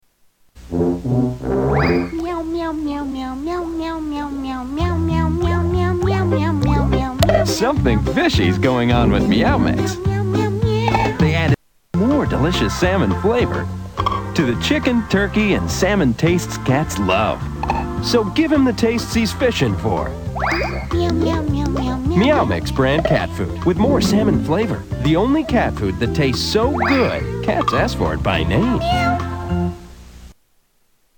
Meow mix commercial
Tags: Media Infinitum Absurdum Repetitious Humor Experiment Funny Repeated words